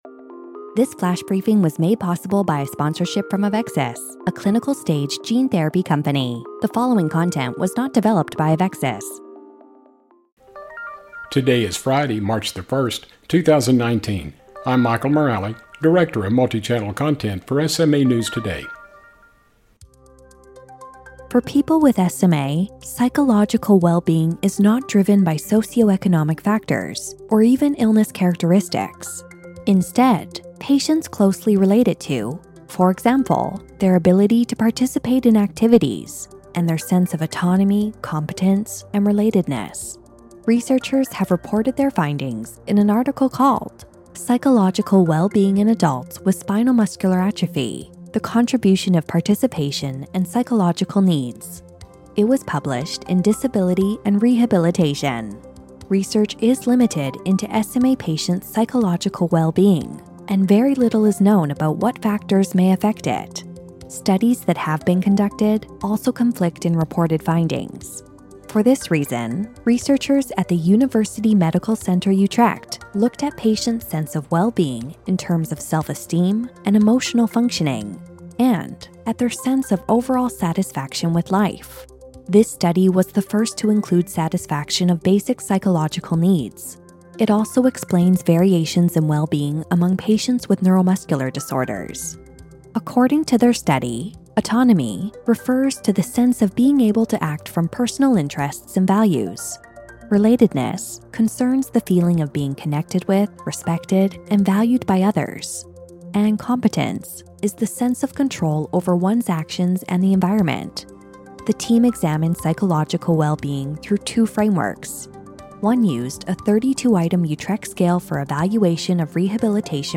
reads from a post in the SMA News Today forums. This post provides advice for SMA parents whose kids are skeptical about taking Spinraza.